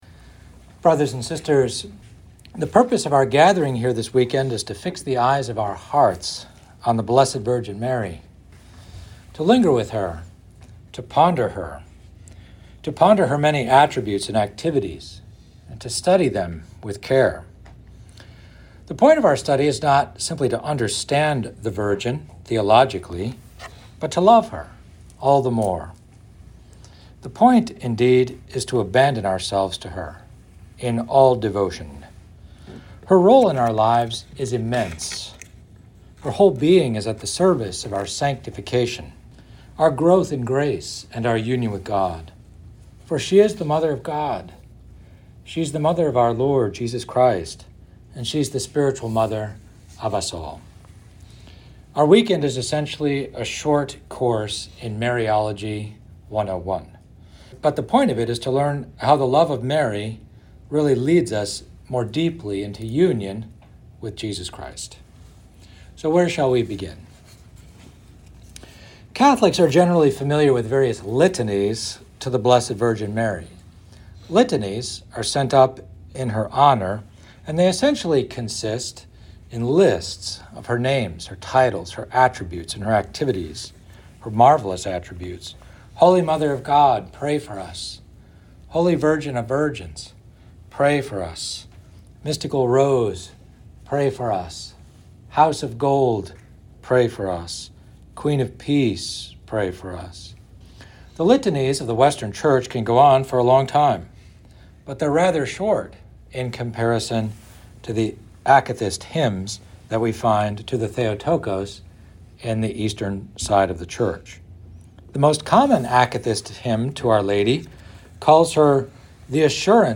This lecture was given on December 8th, 2024, at an Intellectual Retreat at the Dominican House of Studies in Washington, D.C.